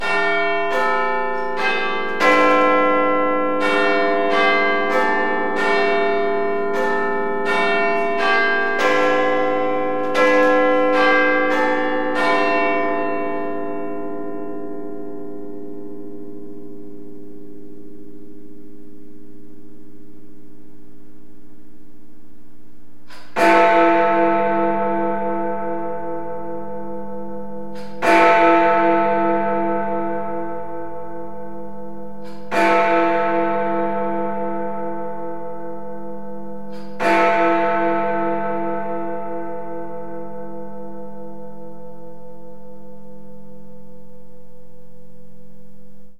Einige weitere Hörbeispiele zu verschiedenen Glocken: